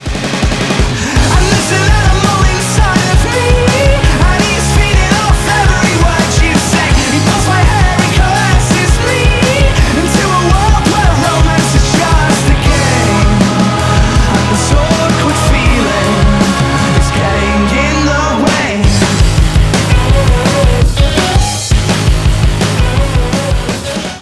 • Качество: 320, Stereo
быстрые
ударные